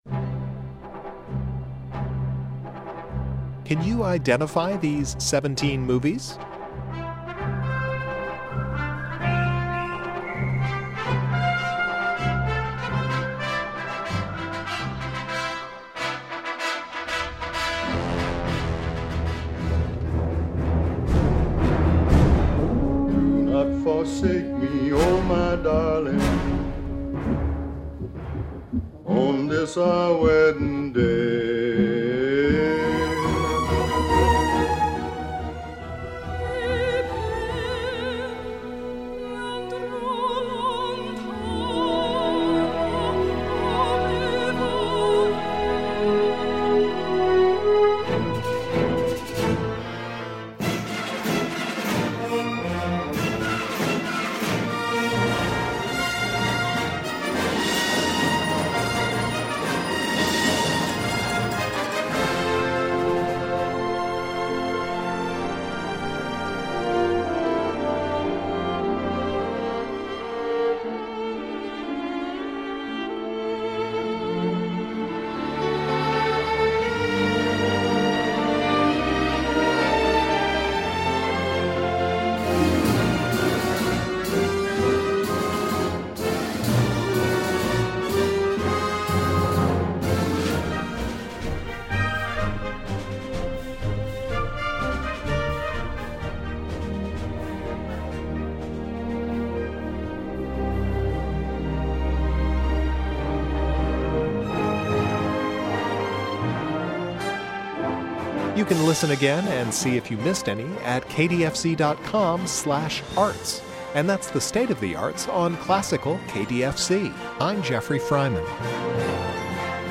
Just in time for 'KDFC Goes to the Movies' leading up to the Oscars, here's a mashup of some iconic film scores...